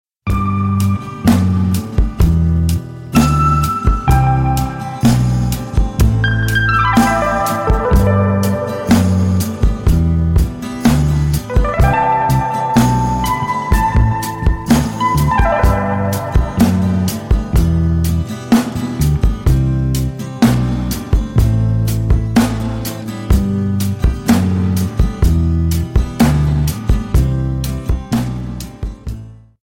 Рингтоны Без Слов » # Рингтоны Альтернатива